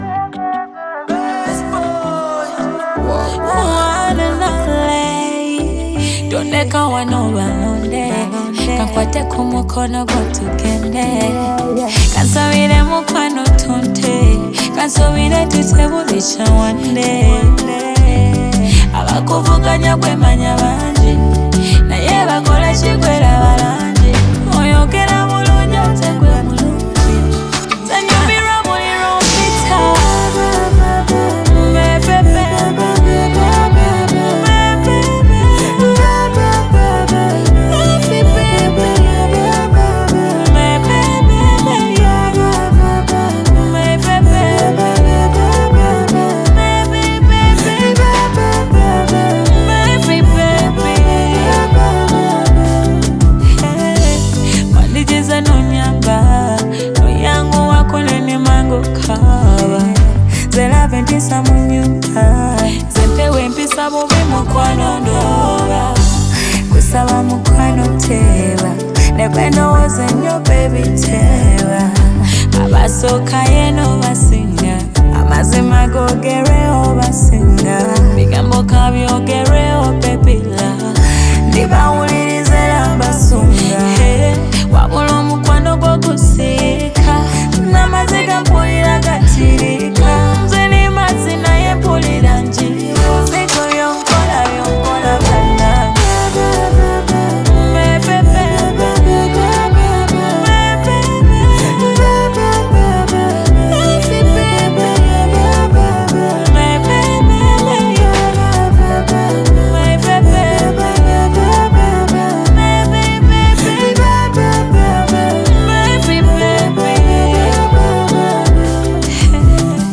African pop and contemporary Afrobeat music